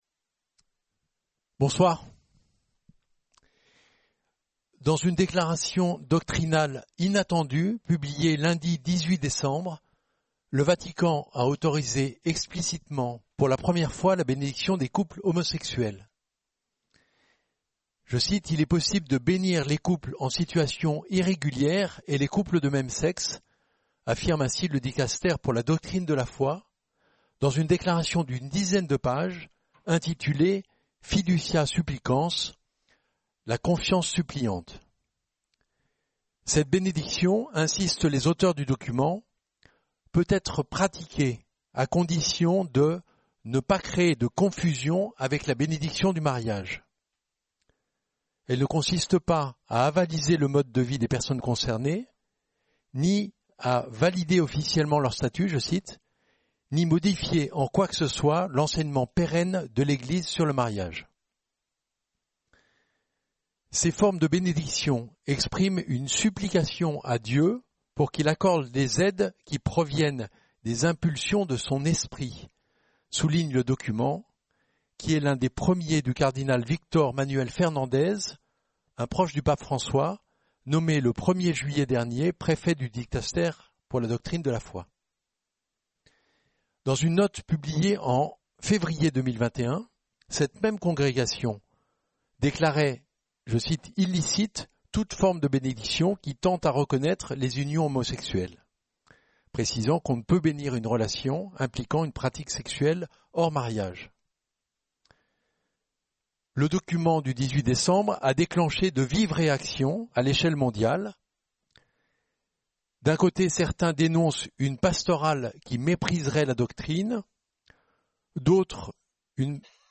Débat animé par